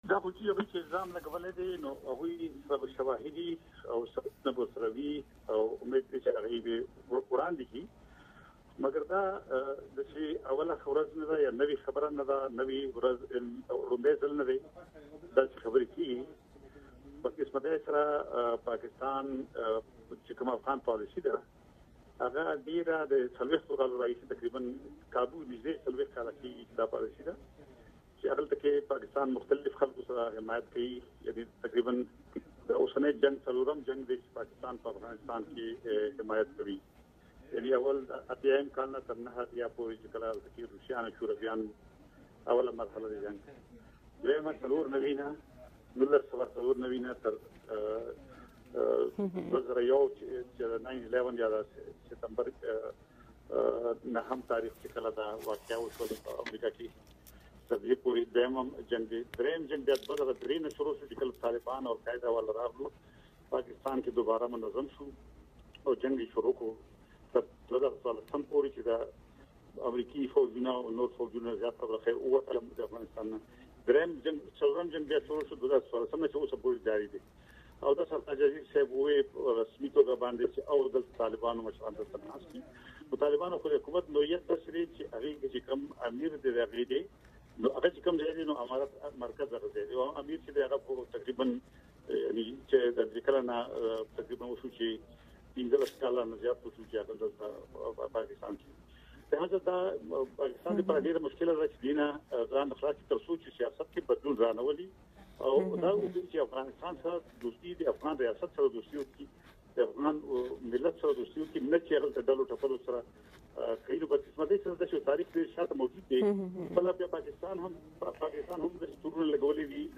مرکې
د افراسیاب خټک سره گمونږ مرکه دلته واورئ